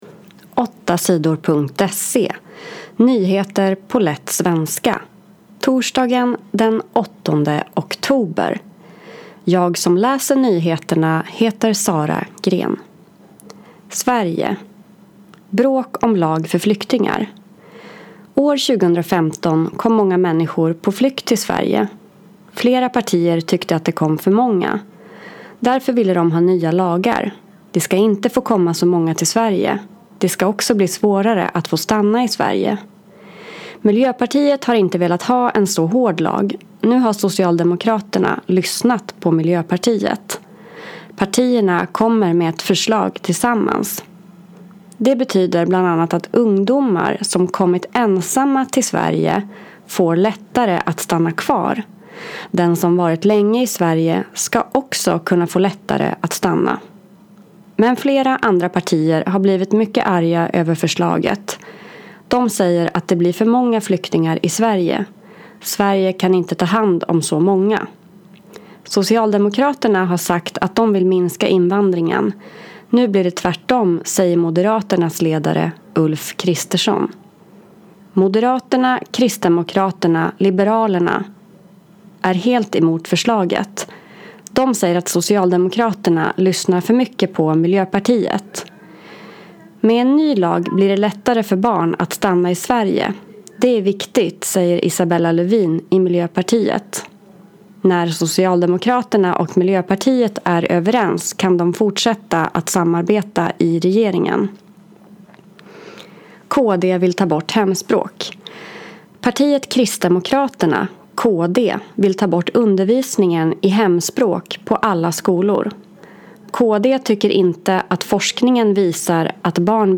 Nyheter på lätt svenska den 8 oktober